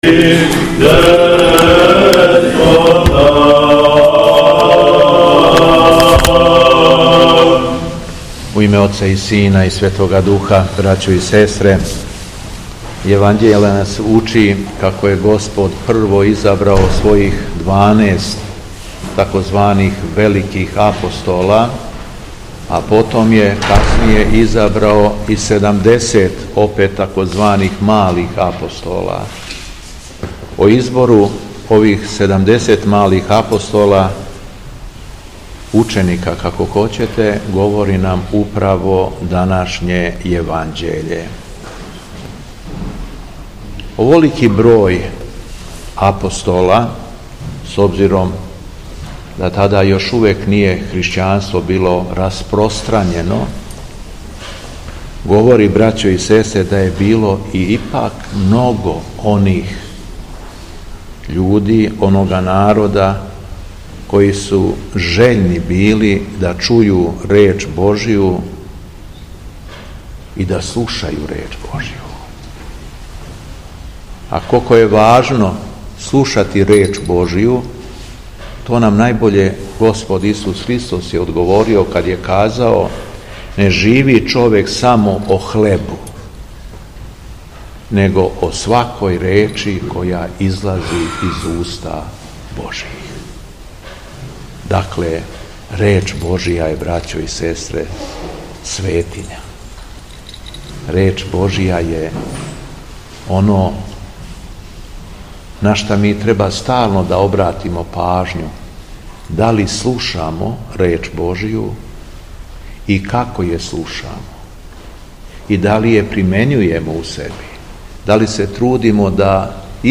У четвртак 5. децембра 2024. године, Његово Високопресвештенство Митрополит шумадијски Г. Јован служио је Свету Литургију у Старој Цркви у Крагујевцу...
Беседа Његовог Високопреосвештенства Митрополита шумадијског г. Јована